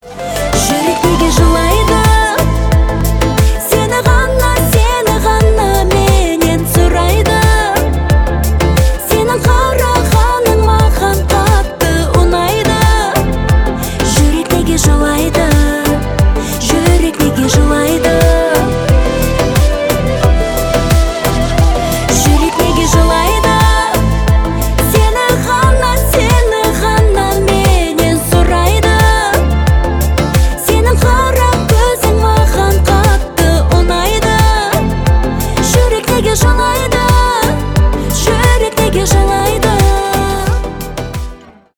поп
танцевальные